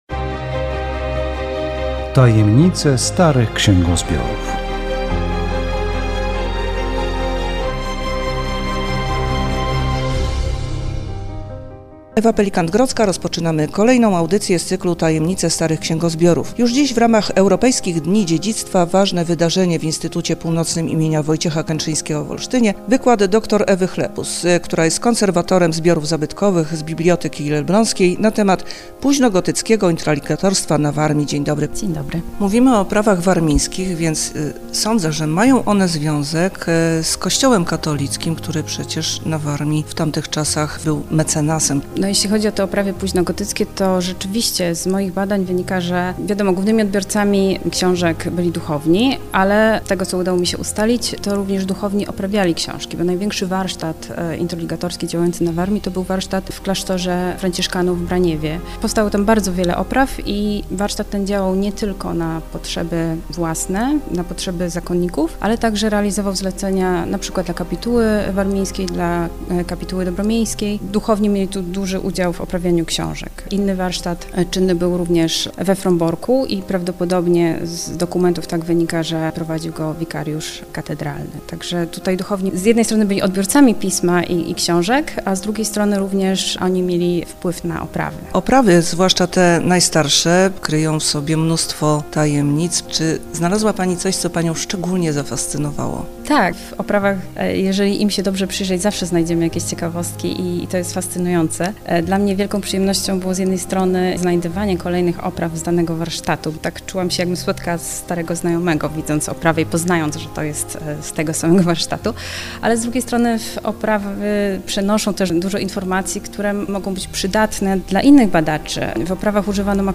Audycja radiowa "Tajemnice starych księgozbiorów
W audycji rozmowa